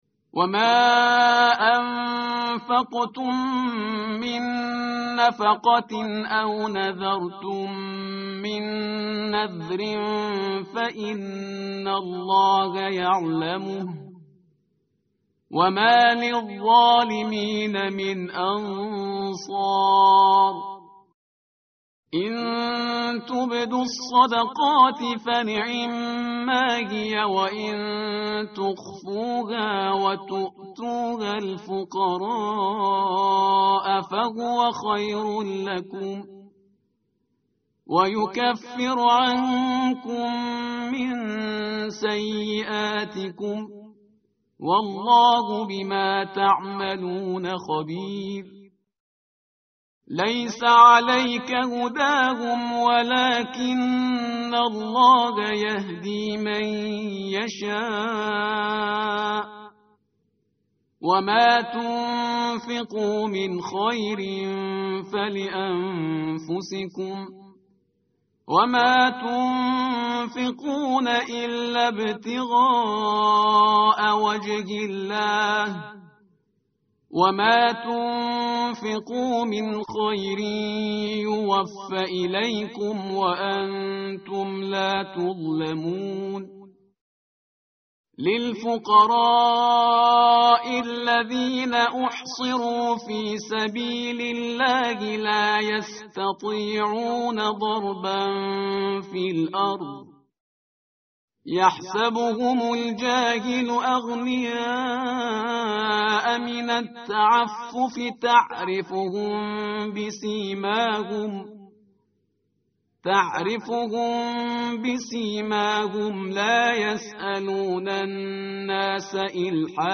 tartil_parhizgar_page_046.mp3